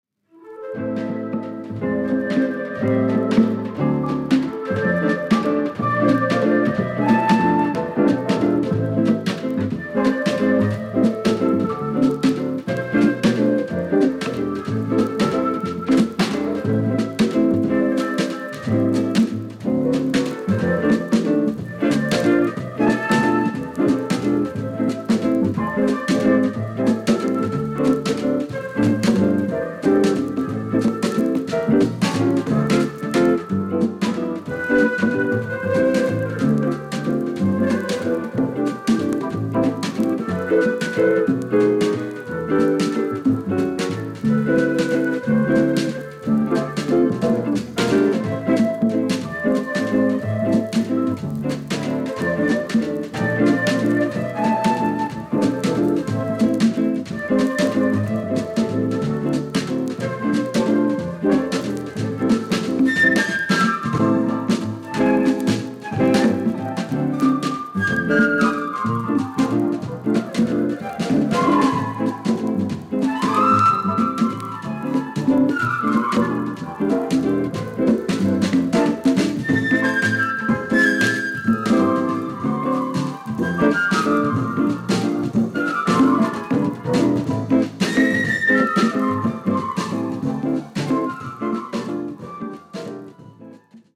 Soundtrackです。